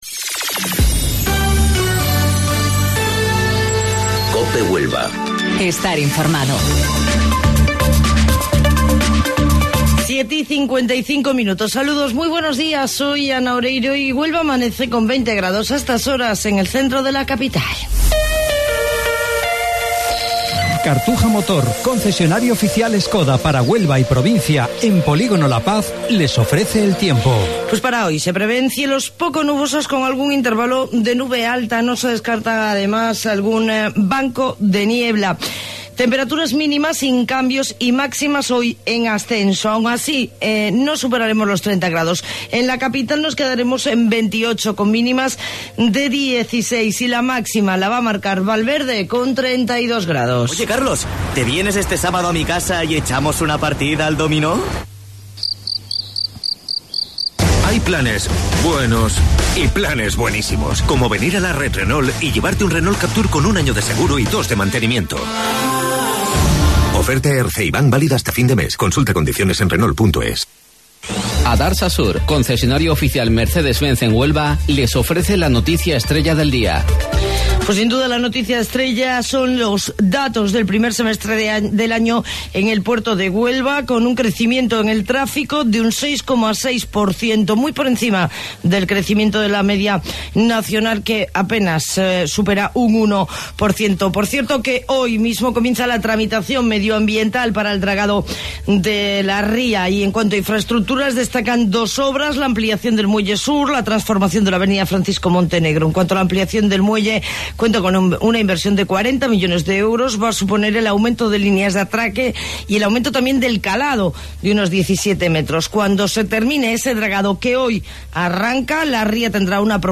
AUDIO: Informativo Local 07:55 del 27 de Junio